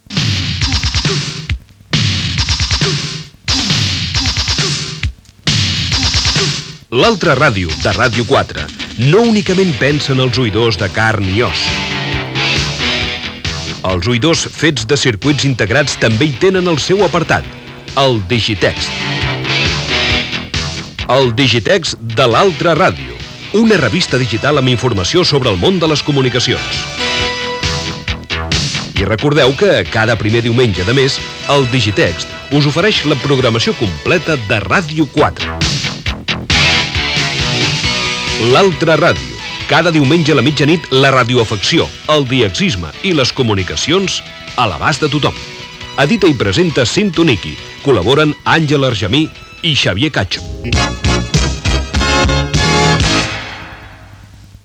Promoció del Digitext.